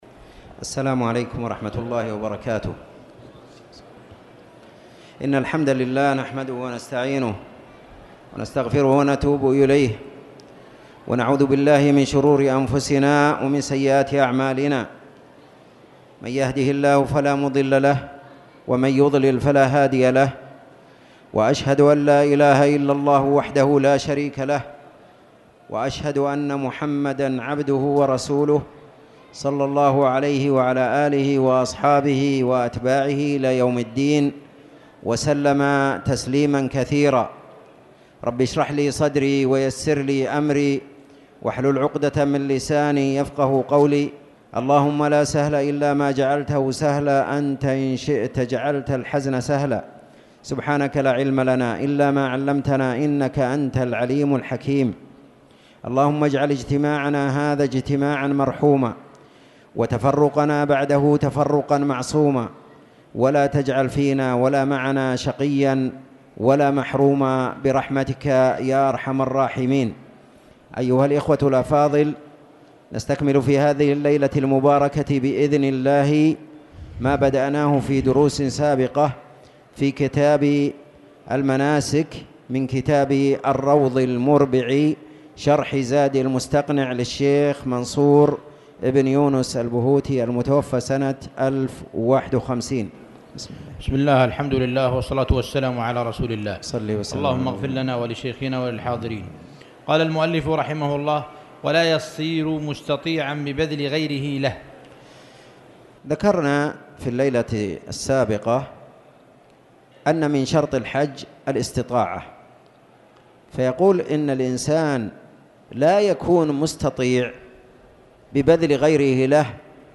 تاريخ النشر ١٥ جمادى الآخرة ١٤٣٨ هـ المكان: المسجد الحرام الشيخ